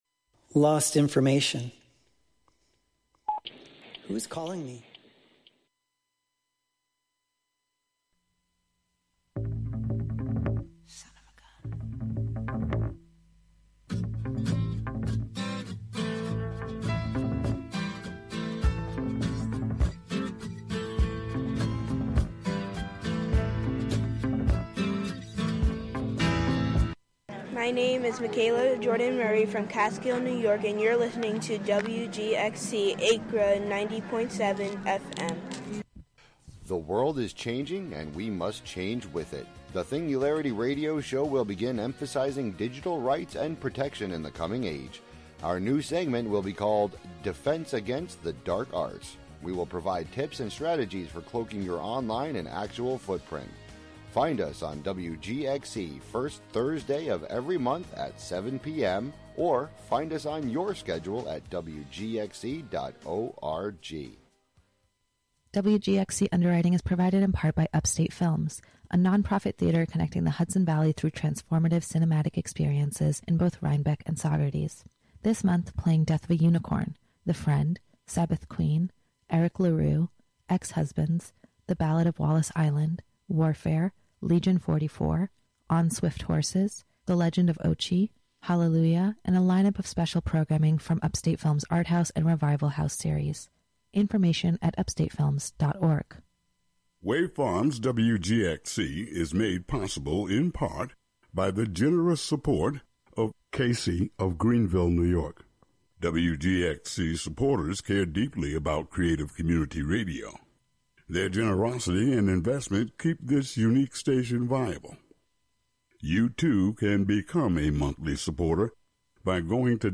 "Uncontaminated Sound" is a conversation series for radio that follows comedians, musicians, fine artists, and actors with the primary objective to gain a particular insight into their creative processes which can only be fully unravelled by truly going behind the scenes.
These features offer listeners real, raw, and authentic conversations.